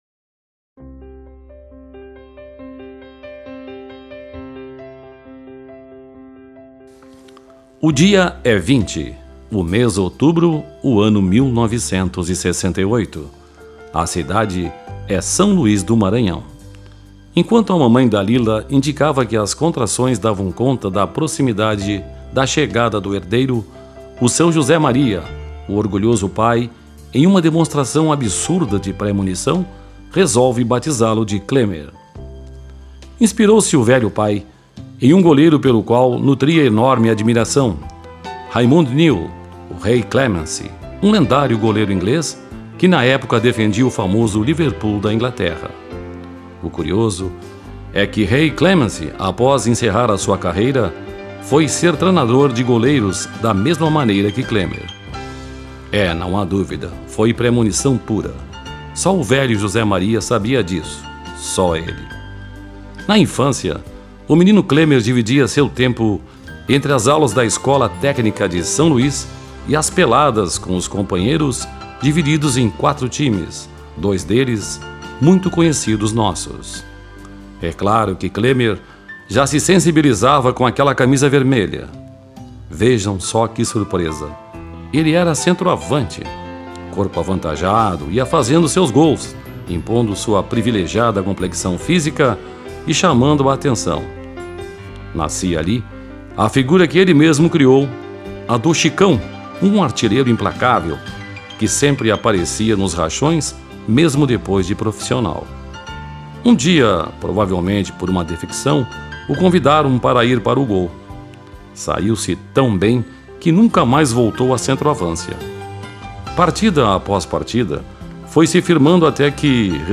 O BLOG ARQUIBANCADA COLORADA, durante o último evento, dia 11, em que homenageou Clemer, apresentou uma locução contando nossa visão do Jogador , do homem, do profissional em si.